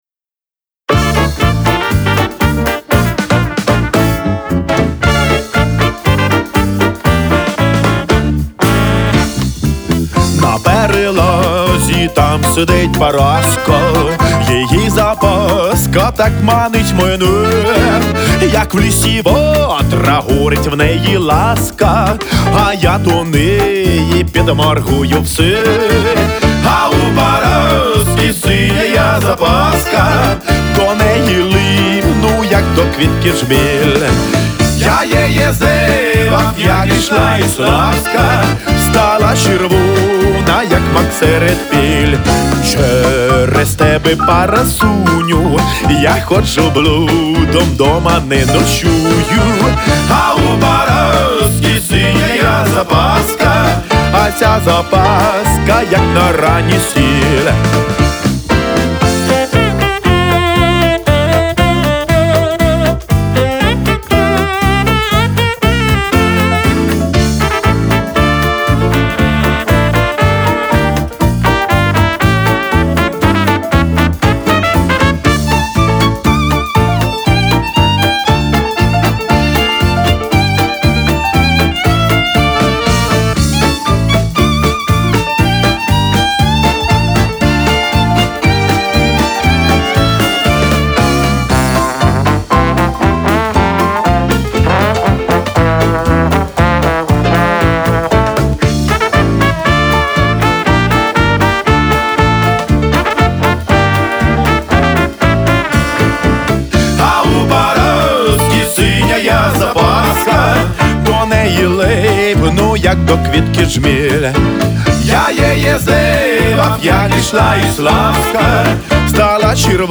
Стиль : retro pop